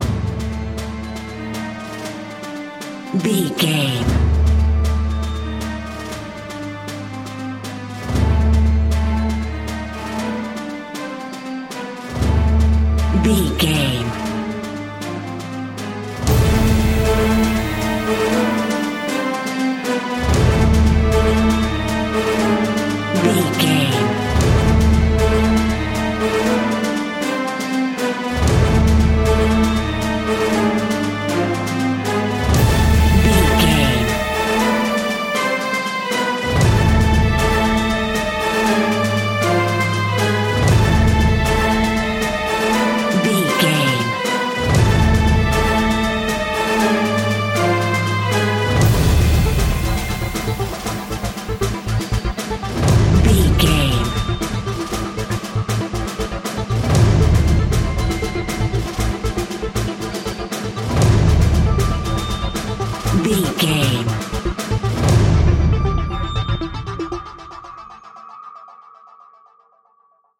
In-crescendo
Aeolian/Minor
Fast
ominous
dark
haunting
driving
strings
drums
percussion
orchestra
synthesiser